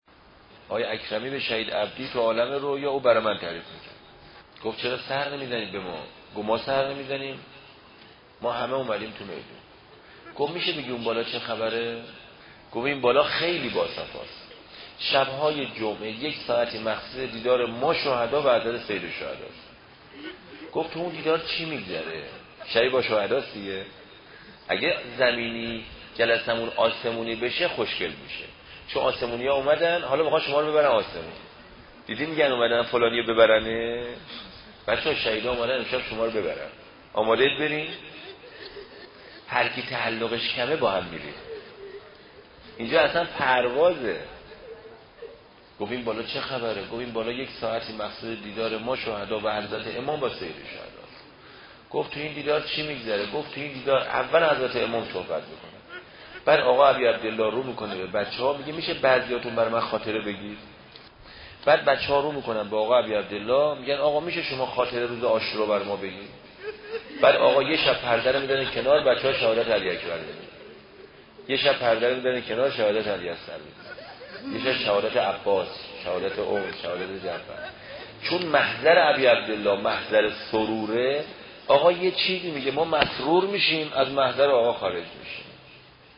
روایتگری